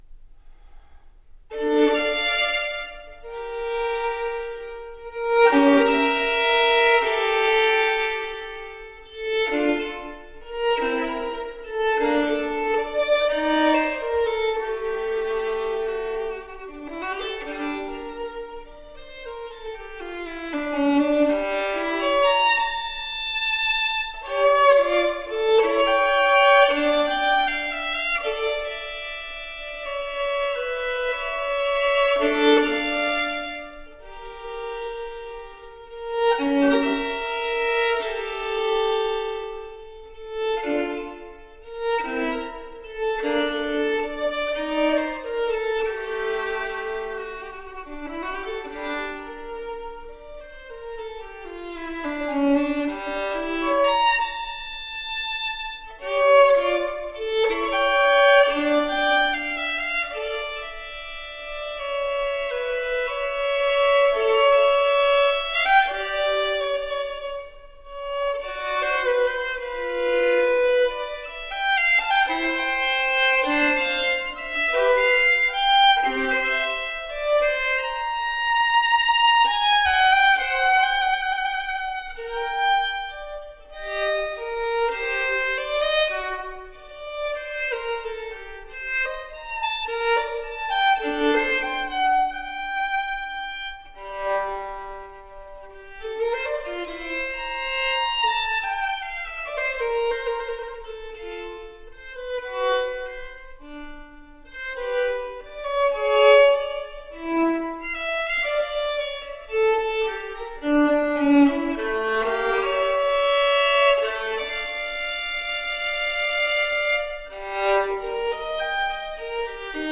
so the resulting music sounds incredibly fresh and alive.
Classical, Baroque, Instrumental